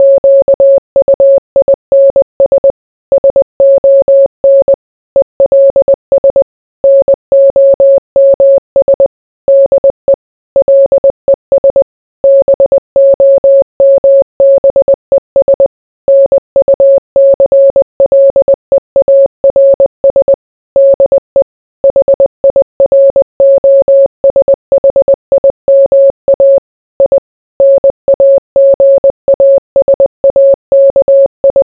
Missatge sonor
morse-1.wav